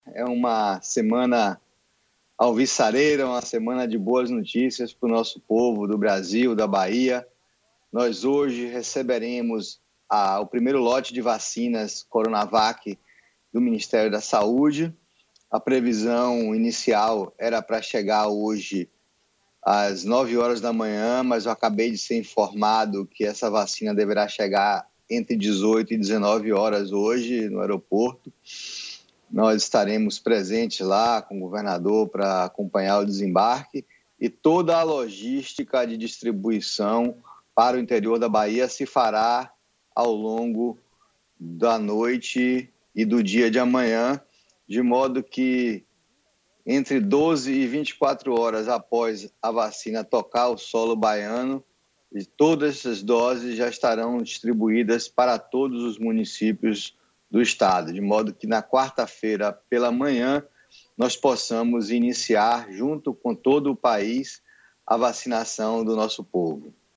Ouça a sonora de Vilas-Boas sobre o recebimento das vacinas: